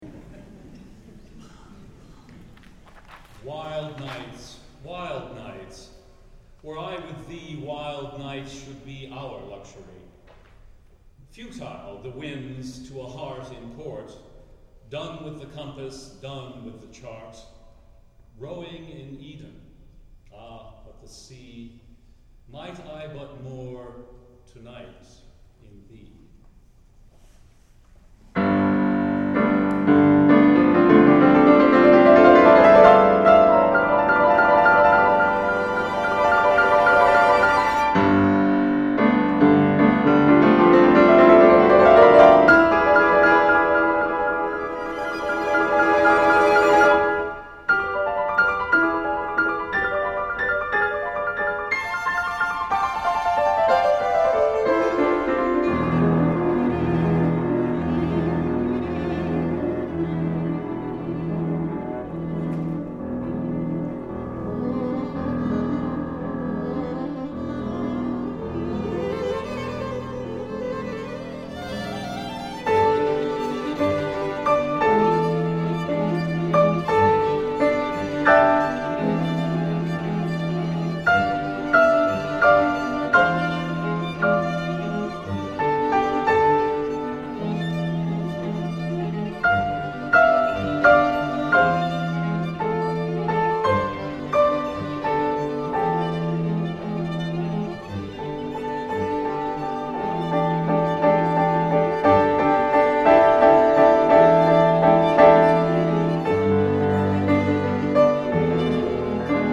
for Piano Quartet (2001)